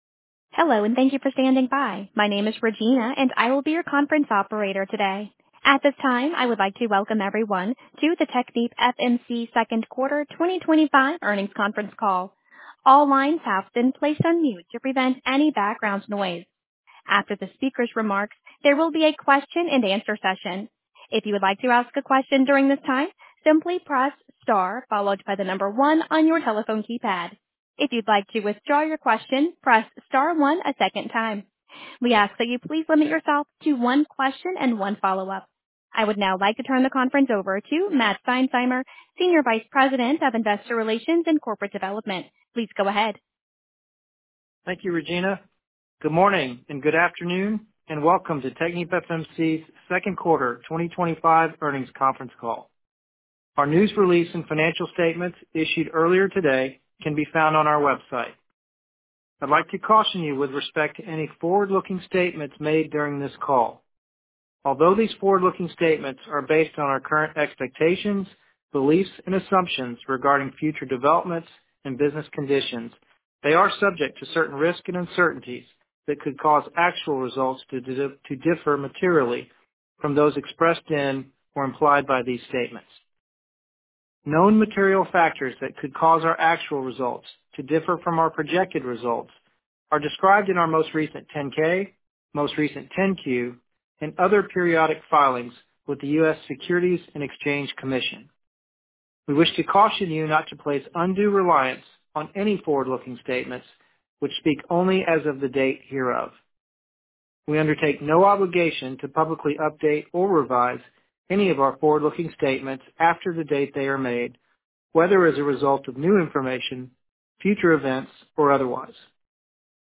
Technipfmc First Quarter 2025 Earnings Conference Call